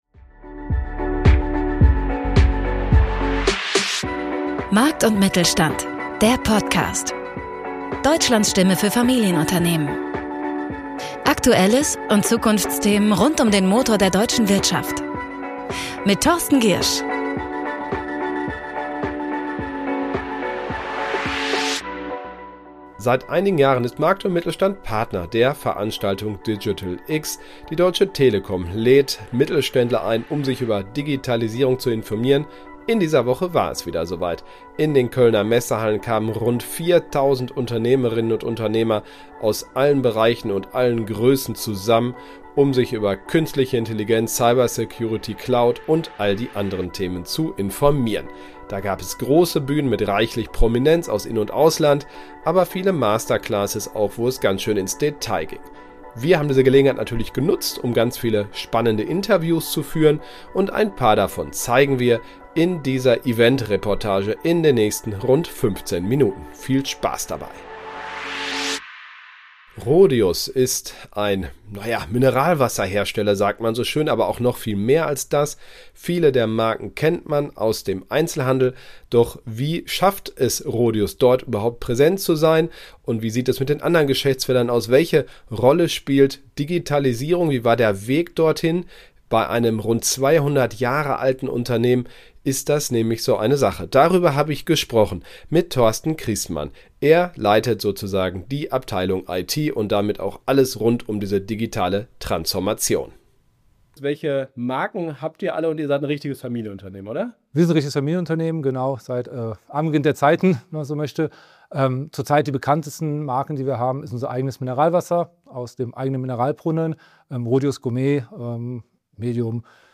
Künstliche Intelligenz, Cloud, Cyber Security und viele weitere Themen - darüber informierten sich in dieser Woche Tausende Unternehmerinnen und Unternehmer in Köln. Die Deutsche Telekom hatte zur "Digital X" eingeladen. Hier einige unserer Interviews, die wir vor Ort gemacht haben.